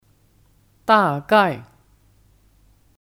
大概 (Dàgài 大概)